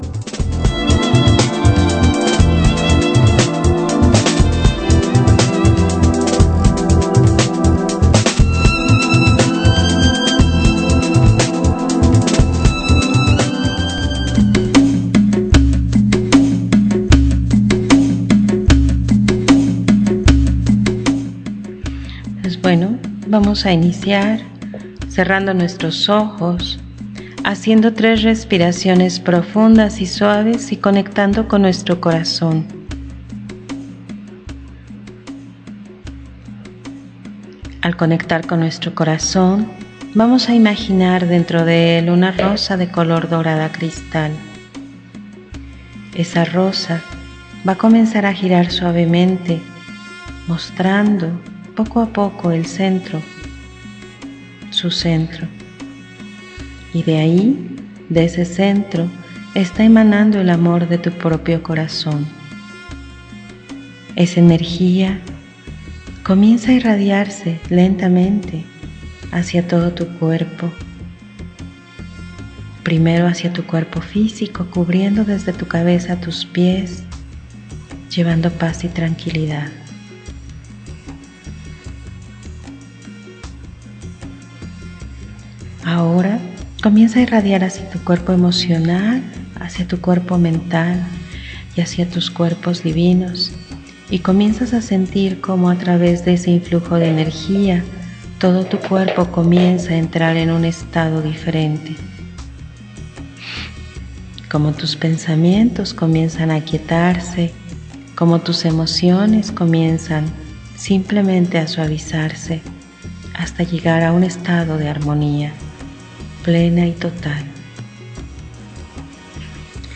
132_Meditacion_Alineacion_con_El_Sol_Central.mp3